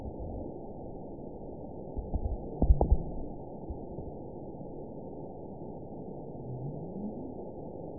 event 920288 date 03/13/24 time 00:32:03 GMT (1 year, 2 months ago) score 9.44 location TSS-AB04 detected by nrw target species NRW annotations +NRW Spectrogram: Frequency (kHz) vs. Time (s) audio not available .wav